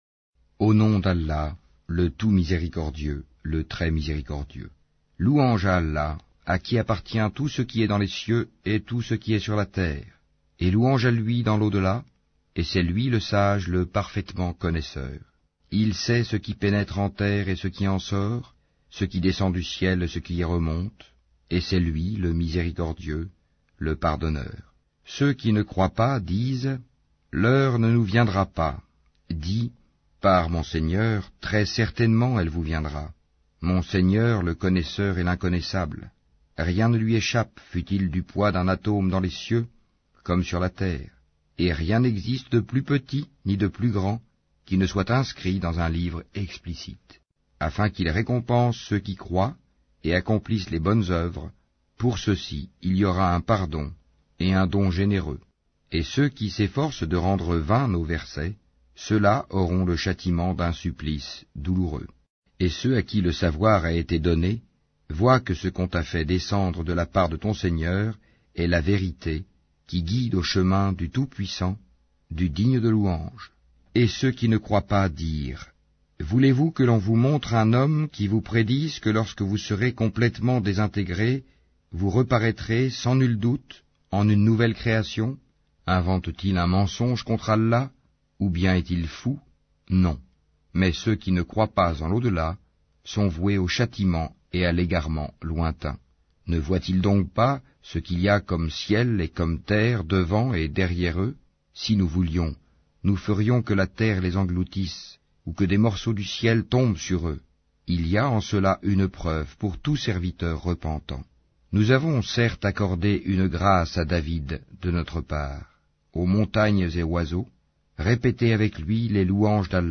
Saba Lecture audio